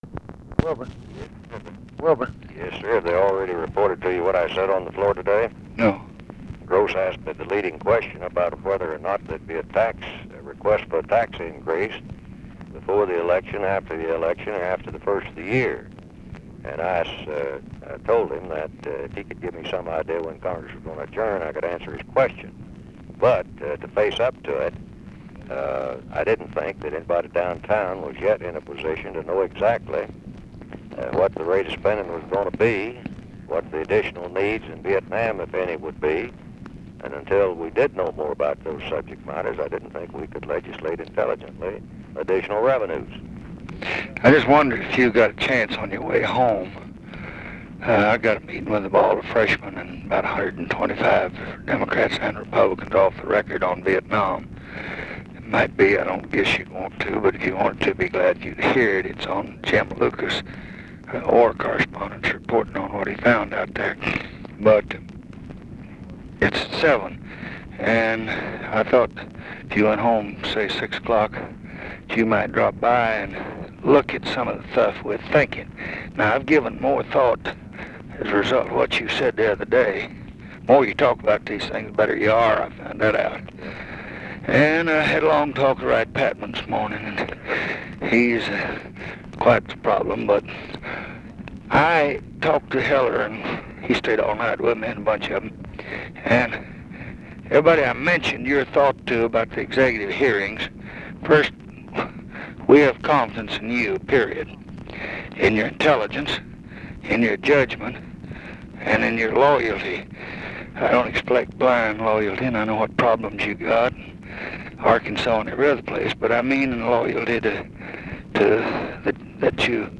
Conversation with WILBUR MILLS, September 6, 1966
Secret White House Tapes